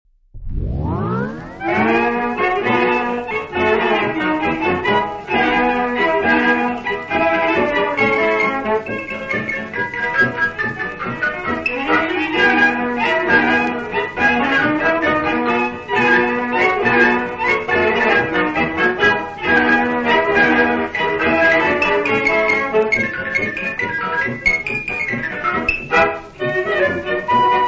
Concerto grosso in Sol minore : 2. Concerto dell'Estro armonico per archi e cembalo / Vivaldi ; adatt.
• Professori d'Orchestra del Teatro alla Scala [interprete]
• Guarnieri, Antonio [direttore d'orchestra]
• registrazione sonora di musica